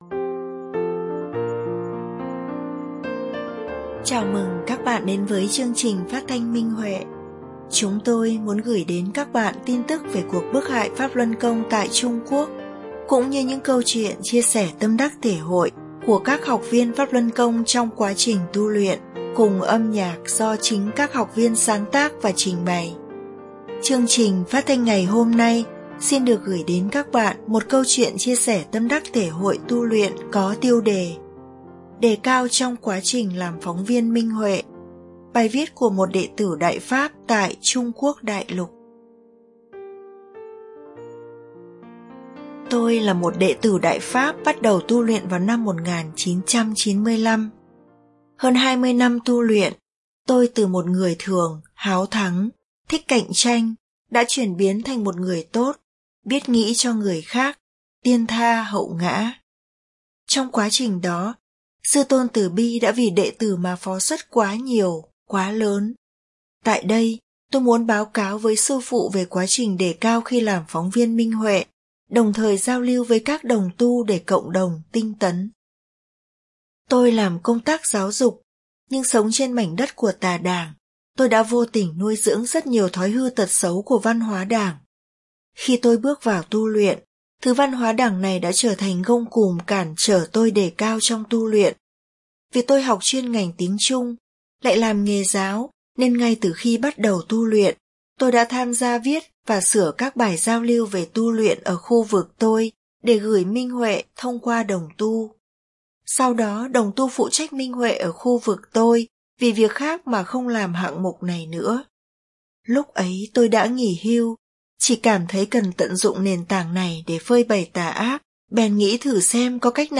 Chương trình phát thanh số 878: Bài viết chia sẻ tâm đắc thể hội trên Minh Huệ Net có tiêu đề Đề cao trong quá trình làm phóng viên Minh Huệ, bài viết của đệ tử Đại Pháp tại Trung Quốc Đại Lục.